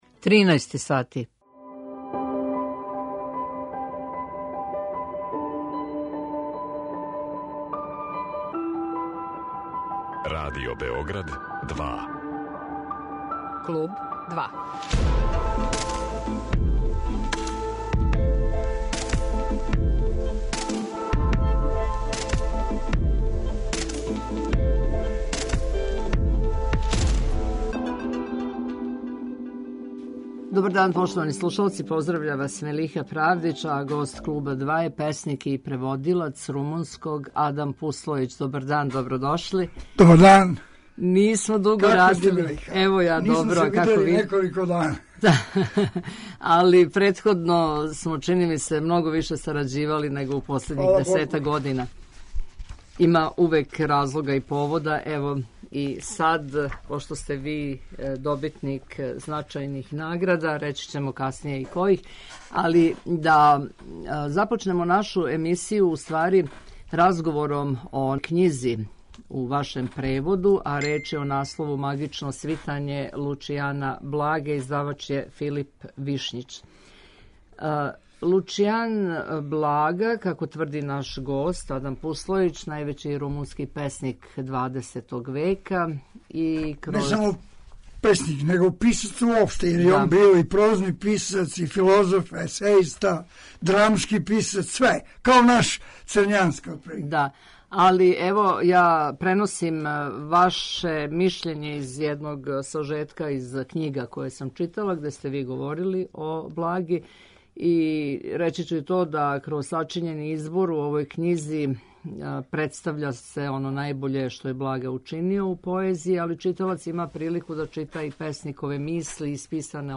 песник и преводилац с румунског